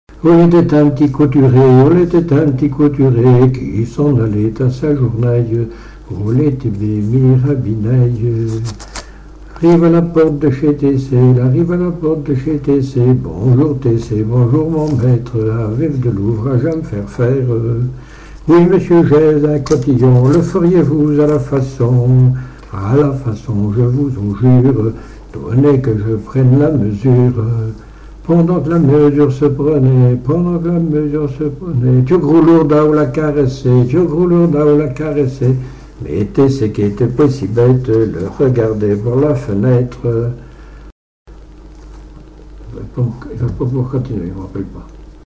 Mémoires et Patrimoines vivants - RaddO est une base de données d'archives iconographiques et sonores.
Patois local
Genre laisse
Pièce musicale inédite